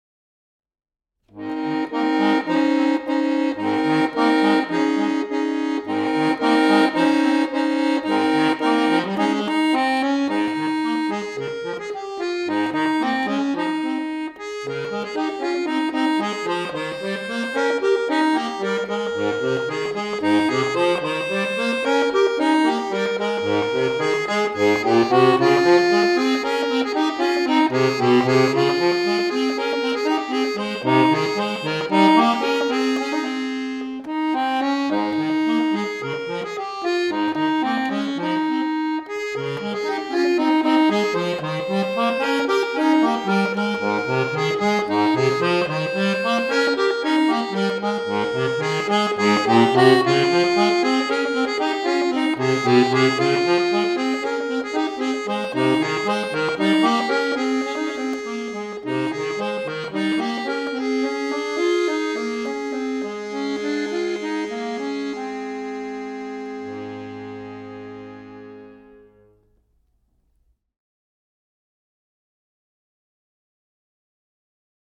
Genre :  Comptine
Enregistrement Instrumental